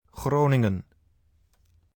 pronunsiashon) ta kapital di e provinsia di Groningen na Hulanda i sentro di e munisipio di e mesun nòmber.